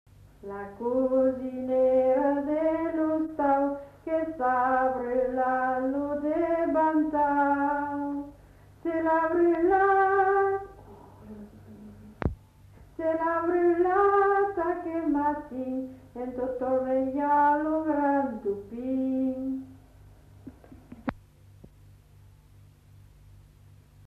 [Brocas. Groupe folklorique] (interprète)
Aire culturelle : Marsan
Genre : chant
Effectif : 1
Type de voix : voix de femme
Production du son : chanté